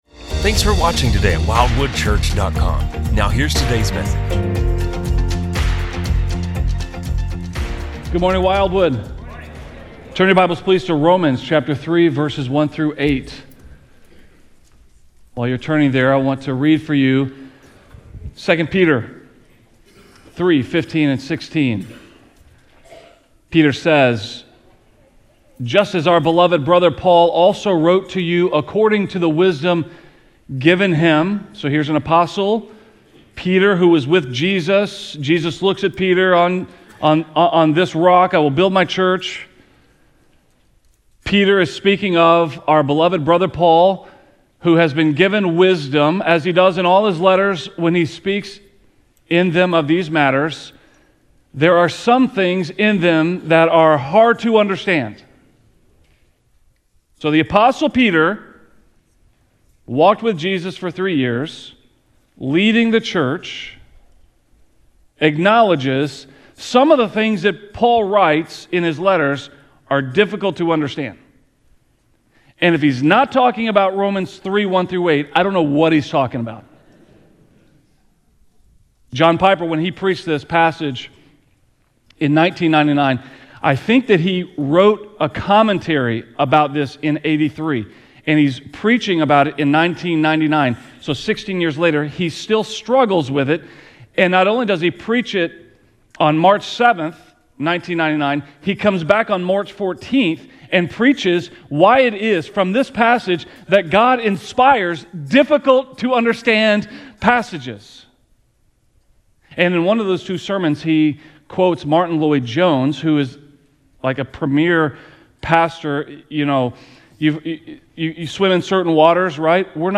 A message from the series "Breakthrough 2021."